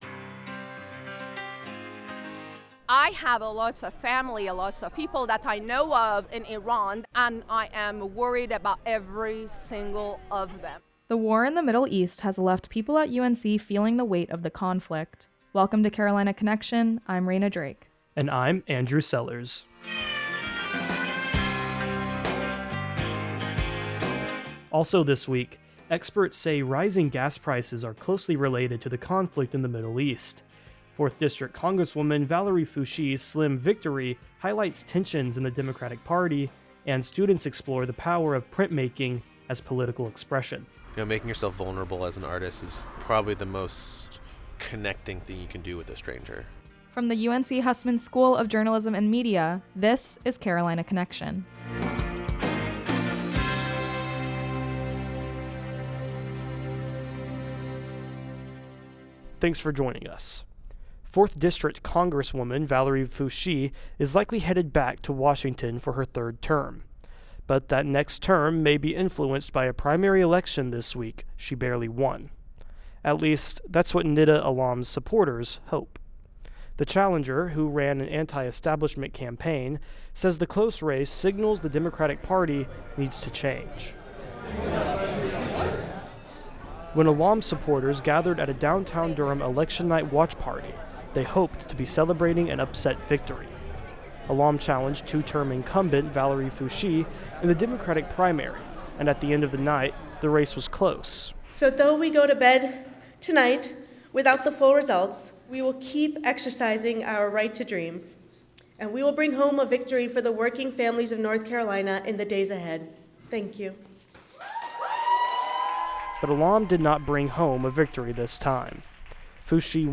Student radio from the University of North Carolina School of Media and Journalism